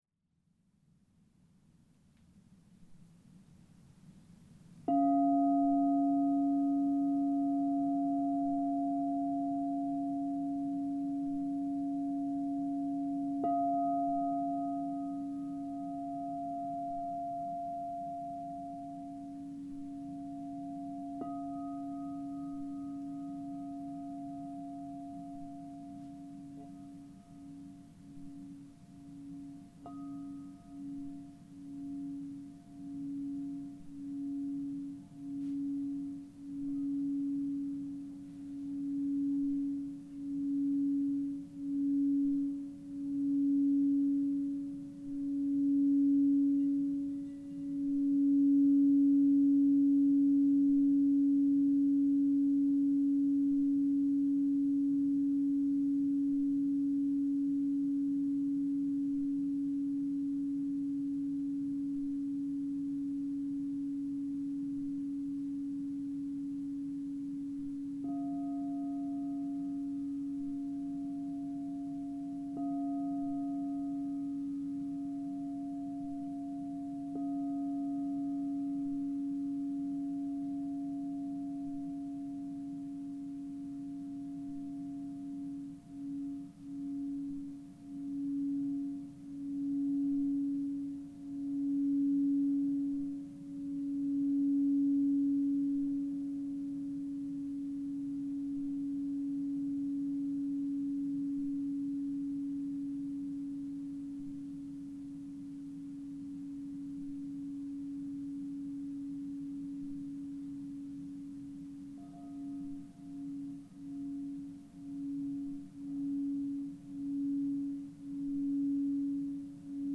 Für das Thema der Heilung von Beziehungen habe ich mein Monochord, die Amethyst-Klangschale und das Solfeggio-Stimmgabelset ausgetestet. Diese besonderen Frequenzen wurden schon im Mittelalter in Heilgesängen verwendet.